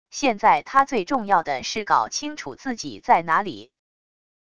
现在她最重要的是搞清楚自己在哪里wav音频生成系统WAV Audio Player